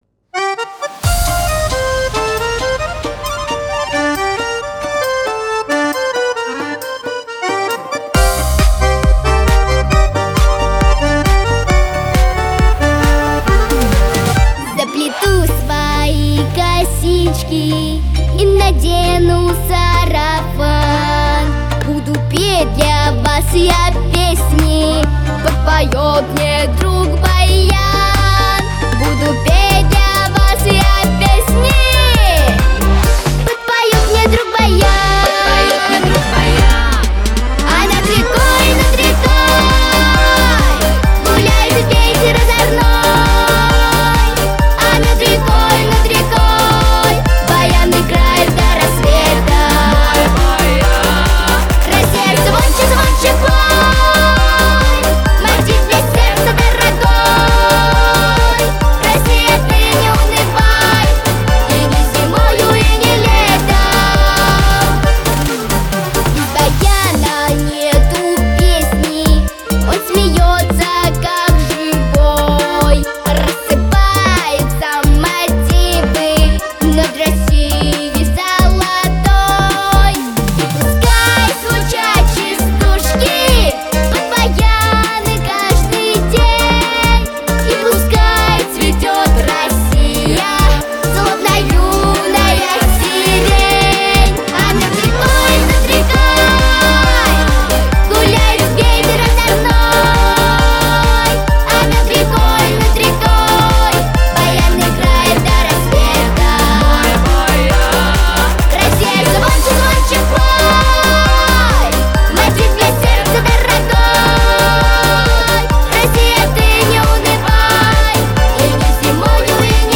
• Качество: Хорошее
• Жанр: Детские песни
народный мотив